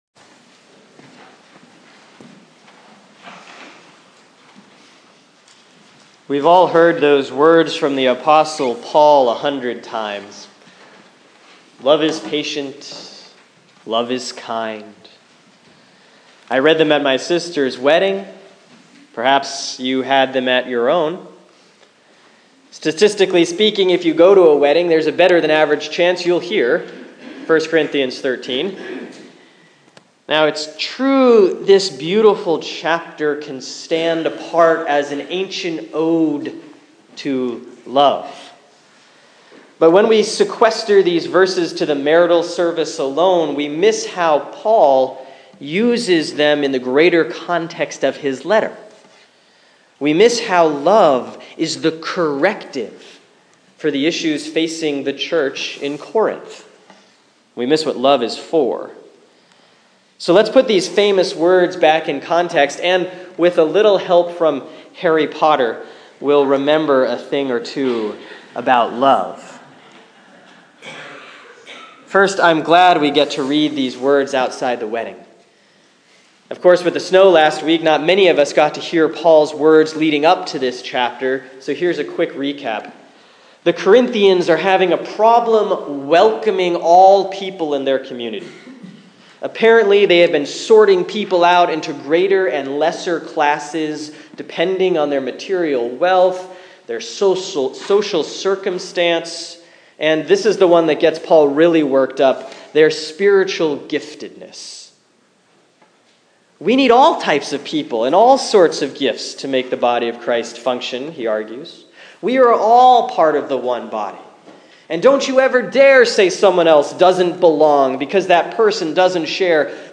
Sermon for Sunday, January 31, 2016 || Epiphany 4C || 1 Corinthians 13:1-13